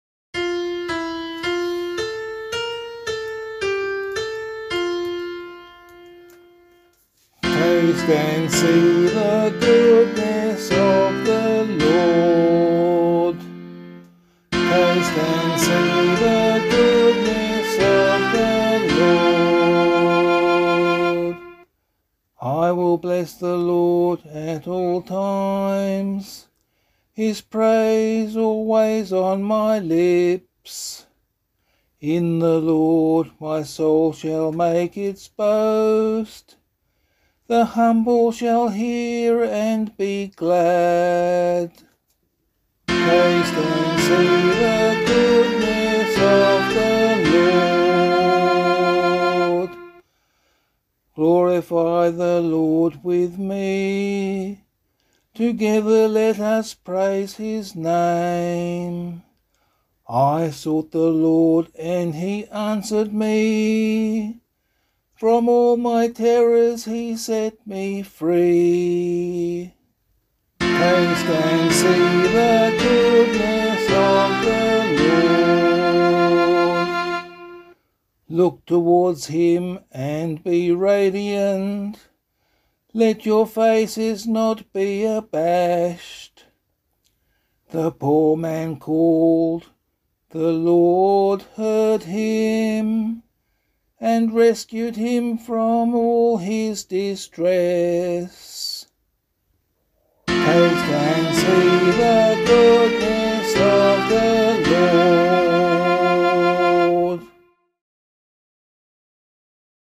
016 Lent 4 Psalm C [LiturgyShare 6 - Oz] - vocal.mp3